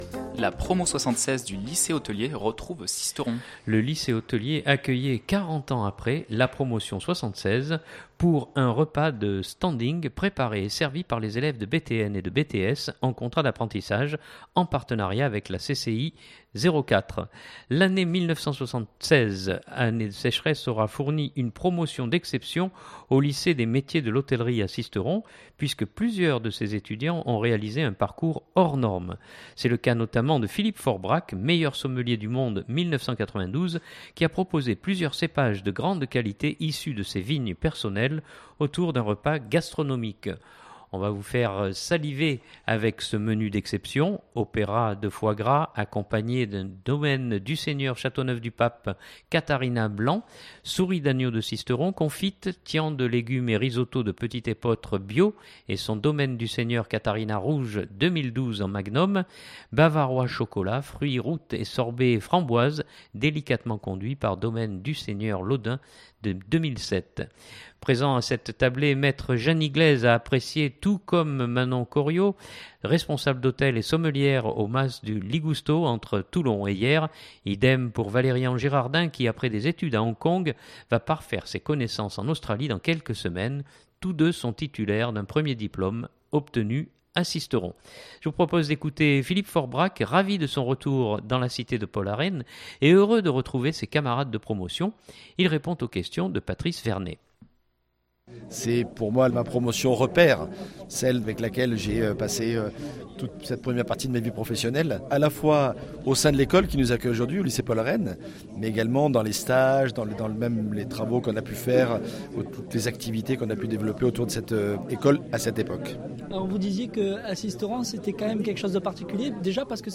Je vous propose d’écouter Philippe Faure-Brac, ravi de son retour dans la cité de Paul Arène et heureux de retrouver ses camarades de promotion.